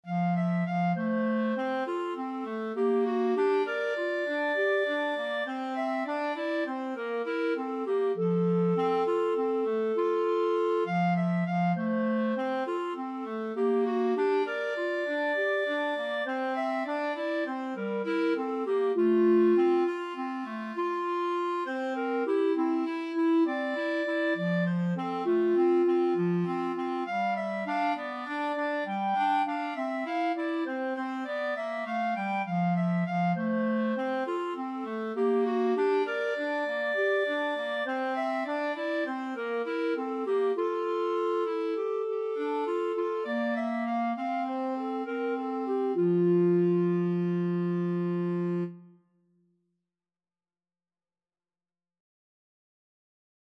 Clarinet 1Clarinet 2
Moderato
9/8 (View more 9/8 Music)